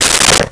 Telephone Receiver Put Down 03
Telephone Receiver Put Down 03.wav